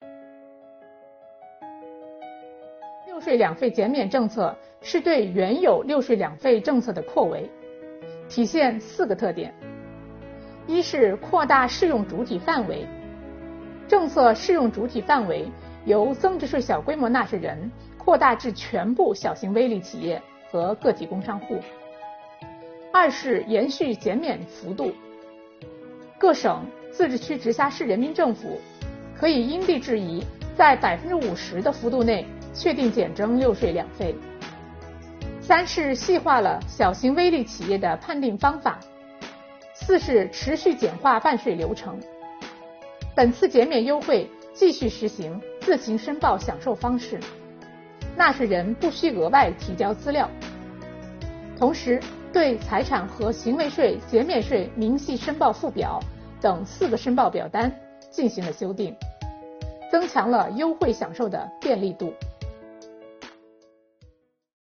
本期课程由国家税务总局财产和行为税司副司长刘宜担任主讲人，解读小微企业“六税两费”减免政策。今天，我们一起学习：小微企业“六税两费”减免政策有哪些特点？